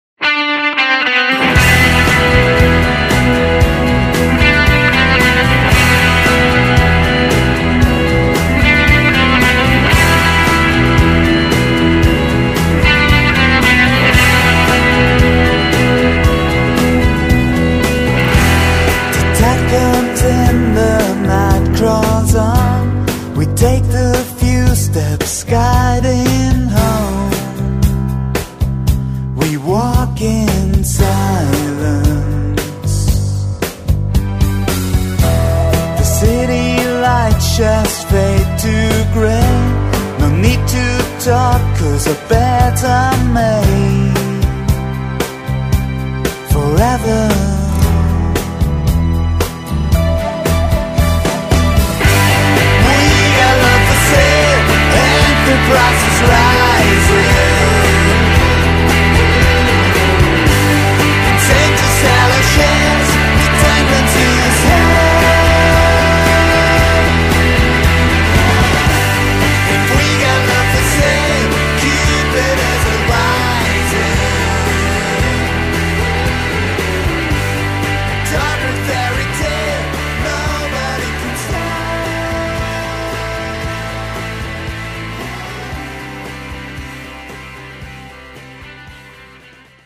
Kraftvoller Pop, der seinesgleichen sucht.